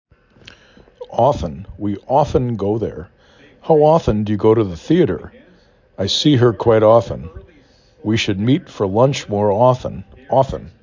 5 Letters, 2 Syllable
aw f ə n
o f (t) ə n